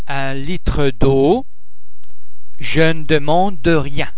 ·[ e ] in consonant rich word boundaries the normally silent final [e] is pronounced :